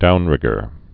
(dounrĭgər)